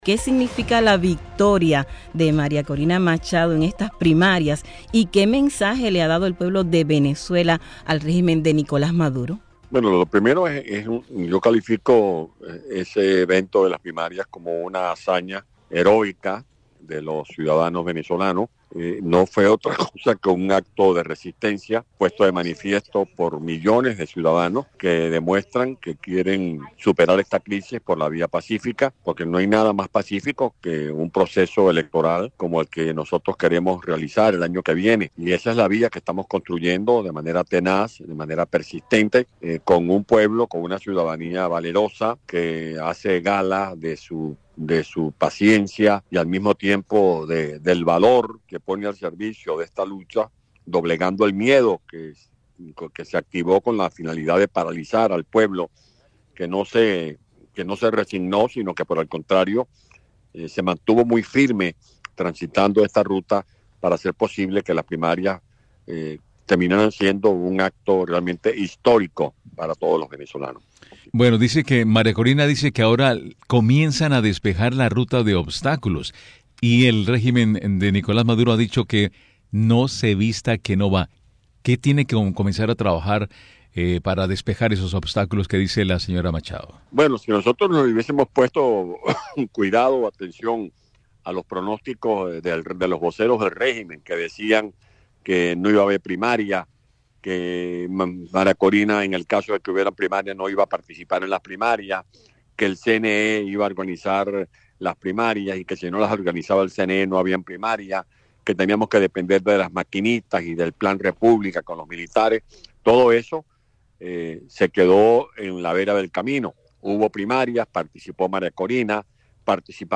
"Llueva, truene o relampaguee, María Corina es nuestra candidata presidencial", afirmó el ex alcalde de Caracas, Antonio Ledezma, en declaraciones a la revista informativa Martí Noticias AM.